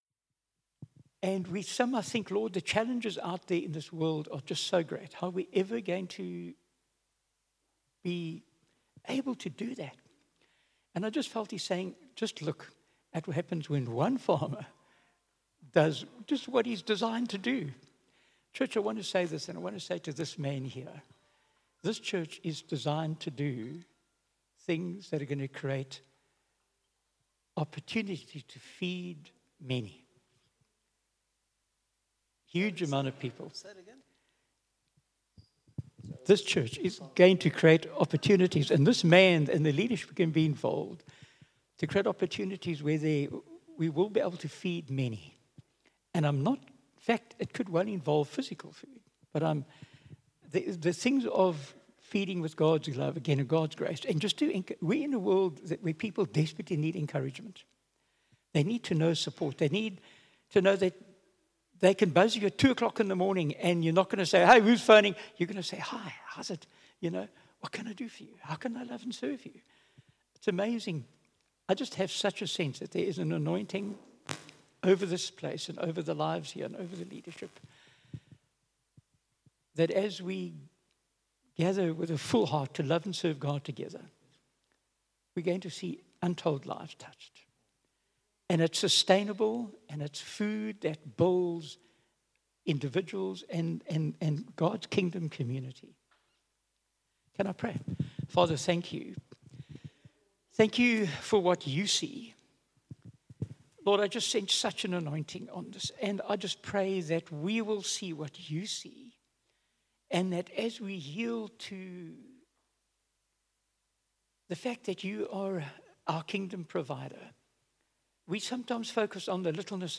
Sunday Service – 17 September